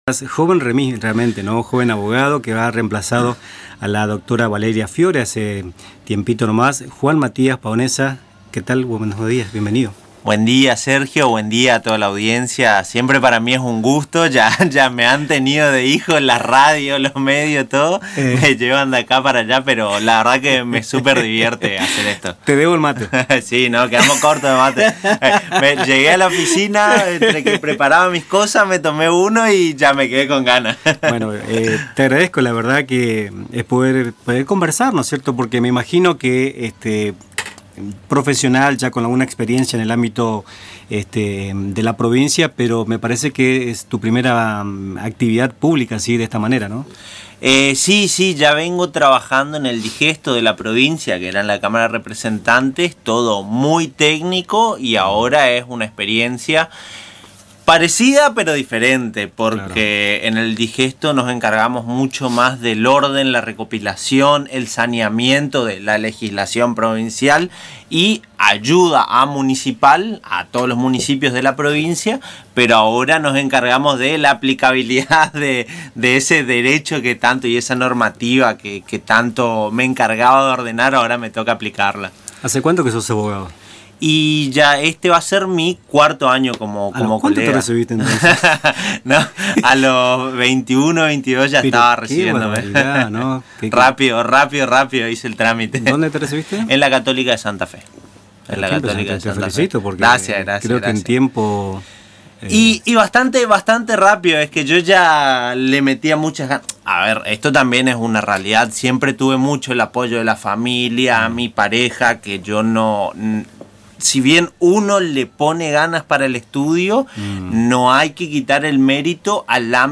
Afirmó Juan Matías Paonessa, el joven abogado de 26 años que asumió recientemente como Defensor del Pueblo de Posadas, en una entrevista con Radio Tupambaé.